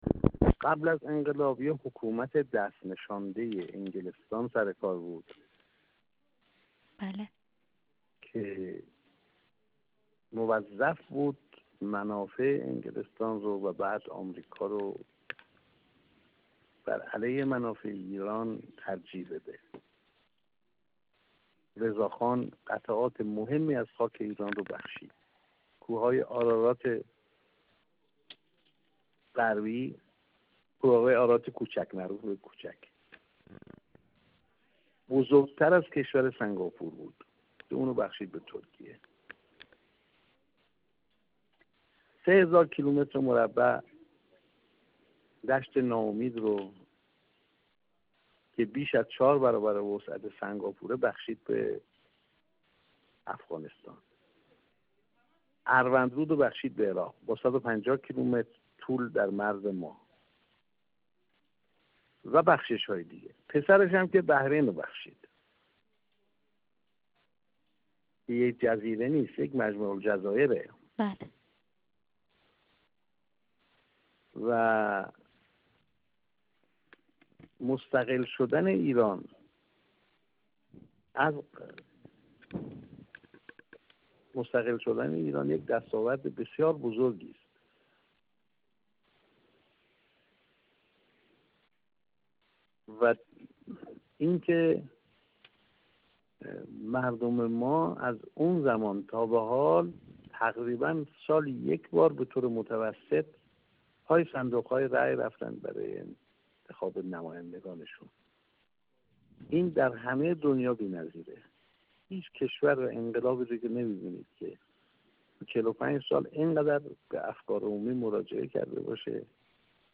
محمدحسن قدیری ابیانه، کارشناس عالی مدیریت استراتژیک و سفیر پیشین کشورمان در استرالیا و مکزیک، در گفت‌وگو با ایکنا، در پاسخ به این سؤال که انقلاب اسلامی در حوزه سیاسی به ویژه حضور مردم وارتقای جایگاه مردم چه تحولی را نسبت به پیش از انقلاب رقم زد؟ گفت: پیش از انقلاب، حکومت دست‌نشانده انگلستان بر سر کار بود که موظف بود منافع انگلستان و سپس آمریکا را علیه منافع ایران ترجیح دهد.